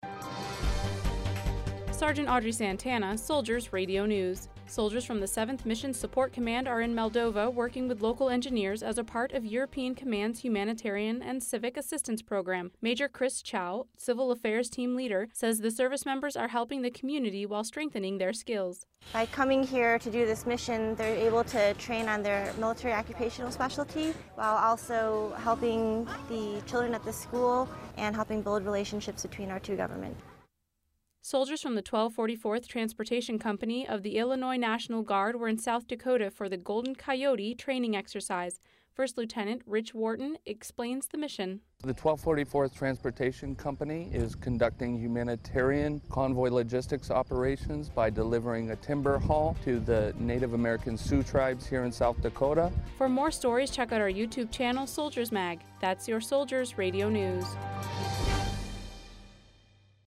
Soldiers Radio News